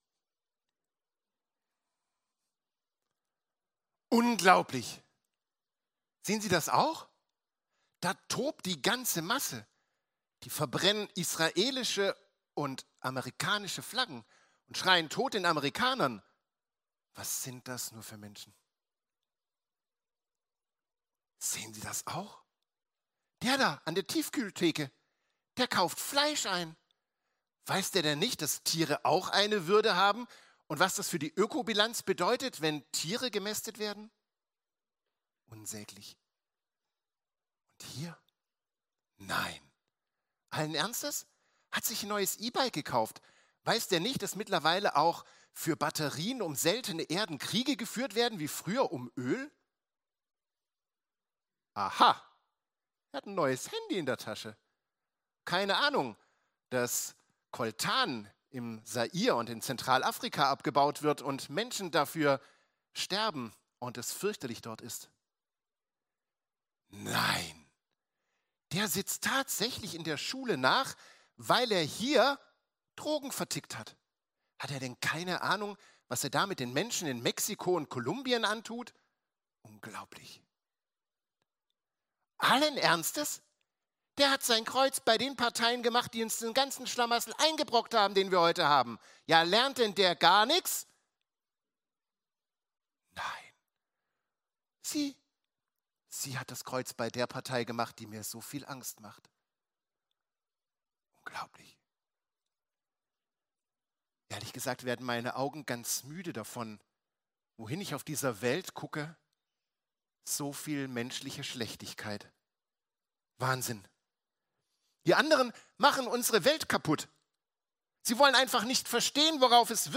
Predigten der Evangelischen Kirchengemeinde Durmersheim